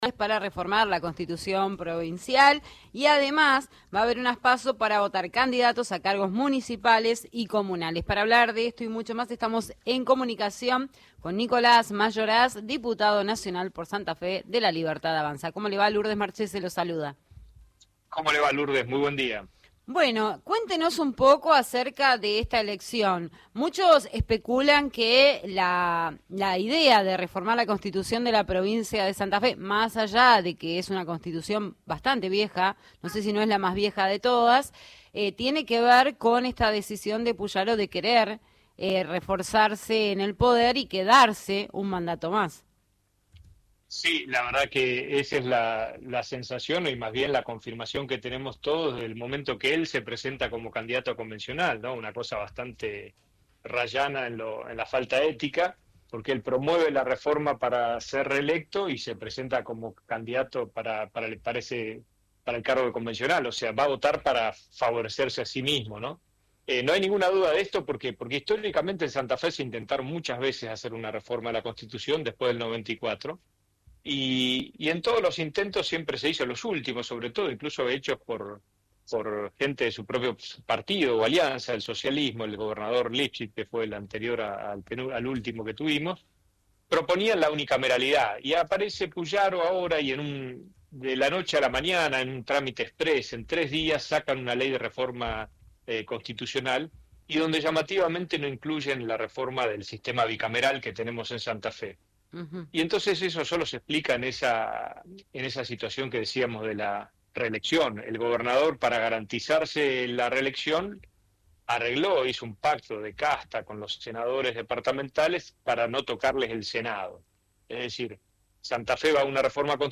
ENTREVISTA A NICOLÁS MAYORAZ